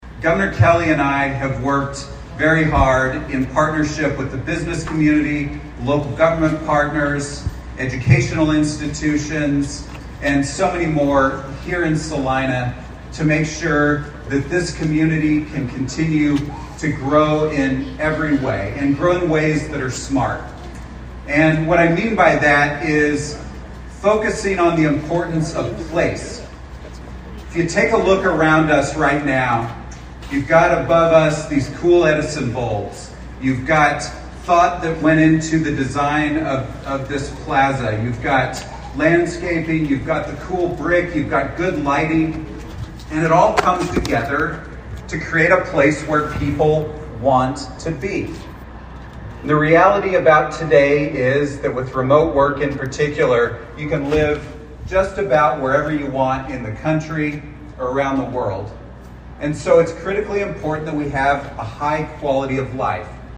Lt. Governor David Toland gave remarks at the event.